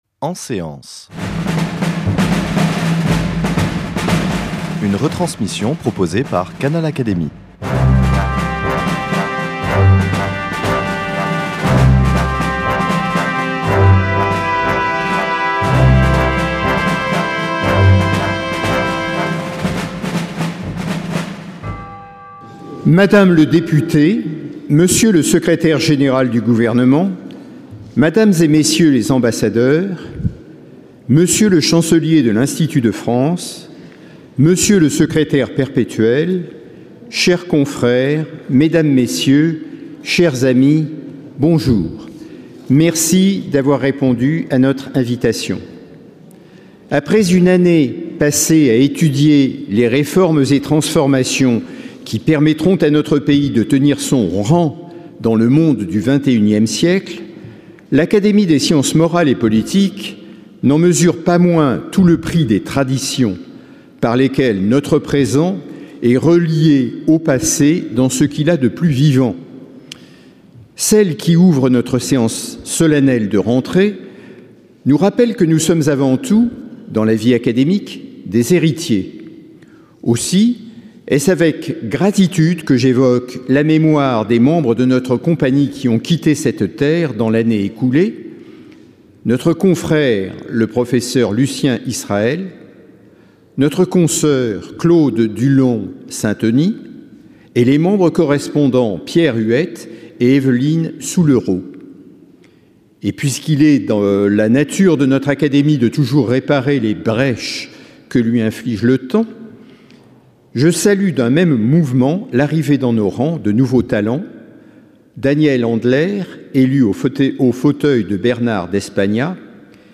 Séance solennelle de rentrée de l’Académie des sciences morales et politiques